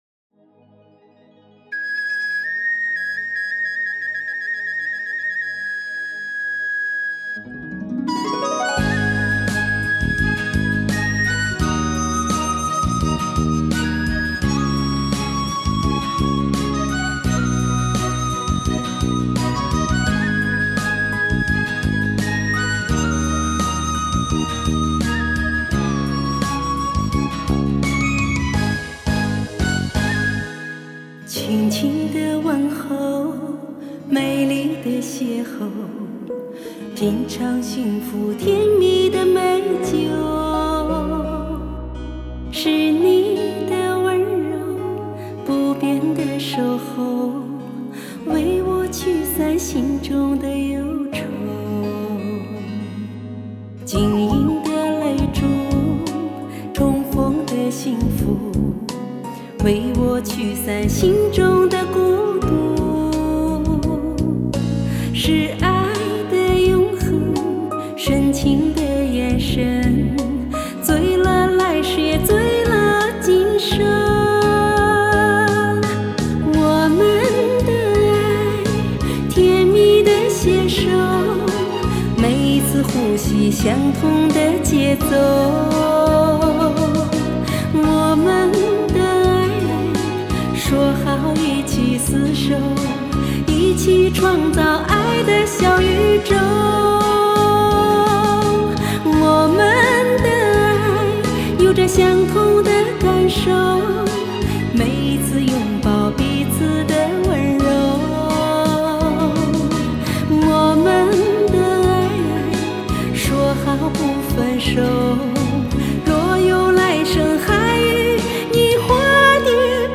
她的歌特别柔美